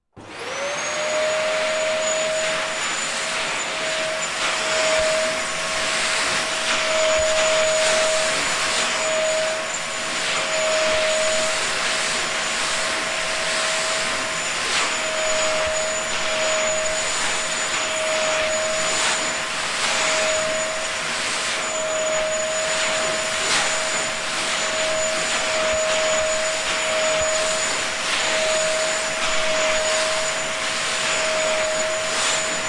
真空 " 真空1
描述：一台戴森吸尘器被打开并在近距离内使用
Tag: 清洁 戴森 胡佛 真空